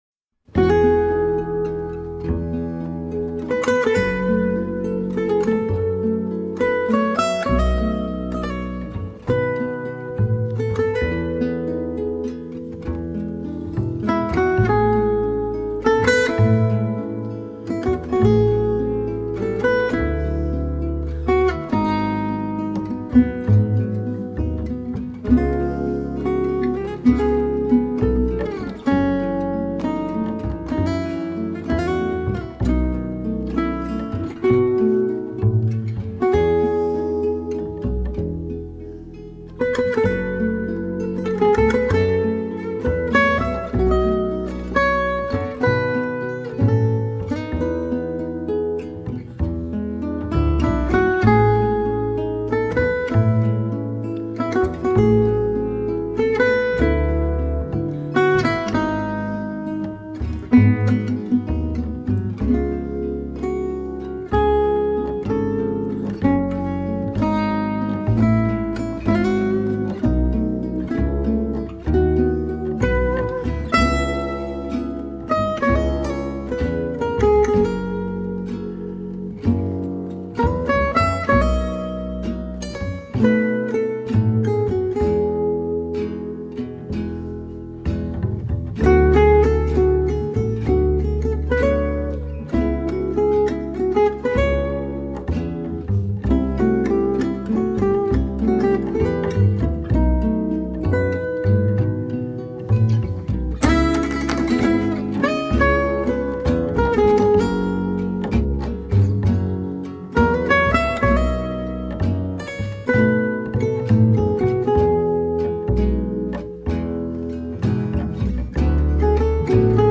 solo guitar
rythm guitare
bass
cymbalum.